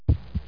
1 channel
beat.mp3